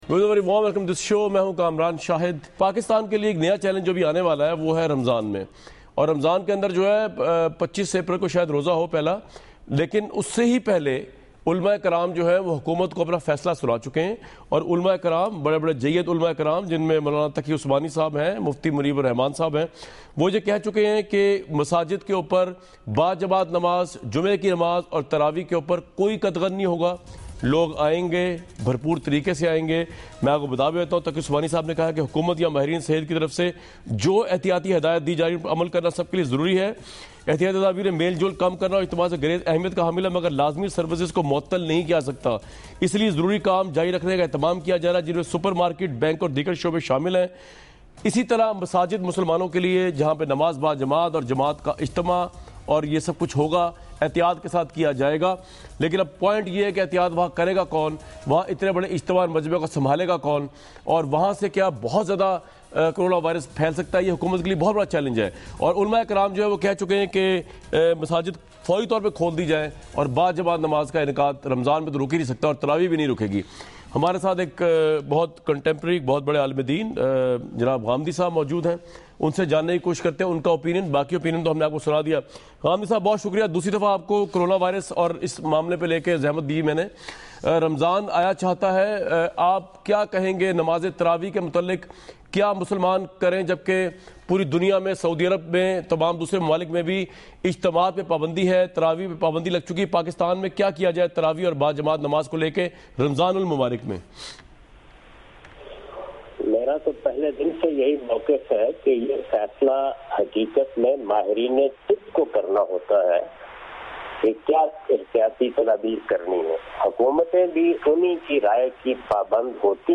Category: TV Programs / Dunya News / Questions_Answers /
In this video Javed Ahmad Ghamidi answer the question about "Corona virus: Namaz e Traveeh Pray at Home or Mosque?".
دنیا ٹی وی کے اس پروگرام میں جناب جاوید احمد صاحب غامدی "کرونا وائرس: نماز تراویح گھر میں ادا کی جائے یا مسجد میں؟" سے متعلق سوال کا جواب دے رہے ہیں۔